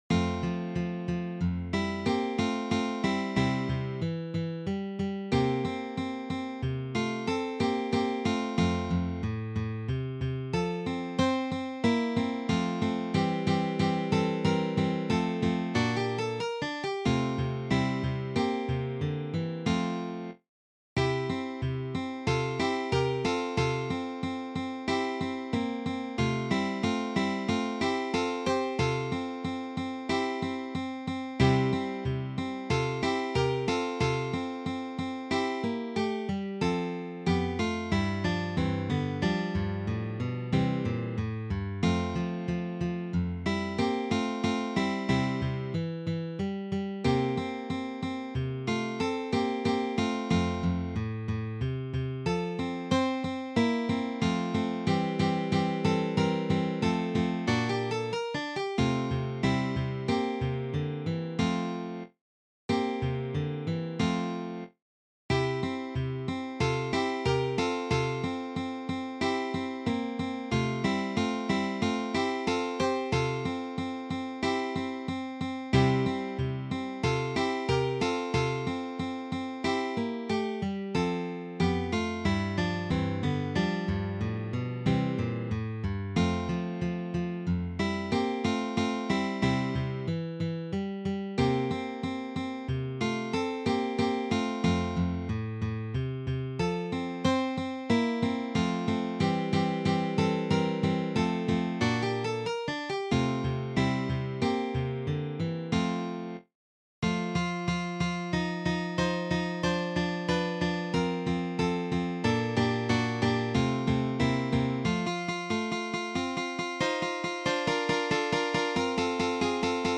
for three guitars